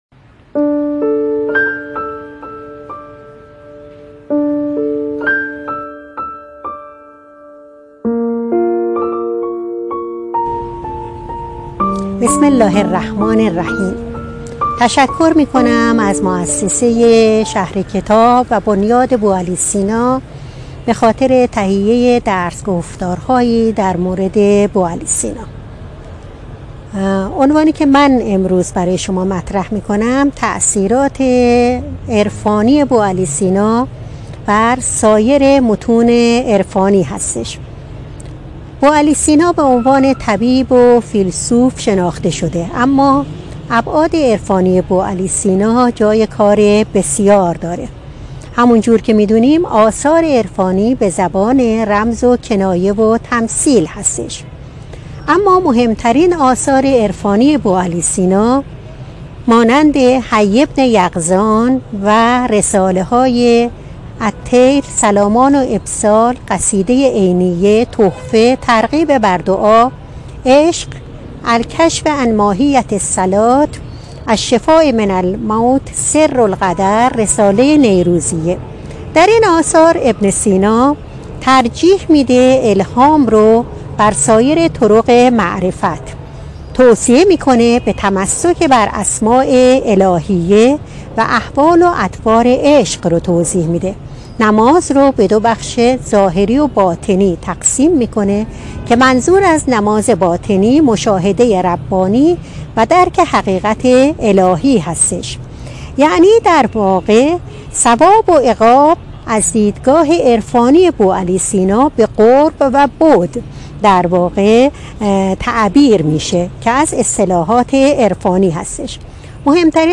این درس‌گفتار به صورت مجازی از اینستاگرام شهر کتاب پخش شد.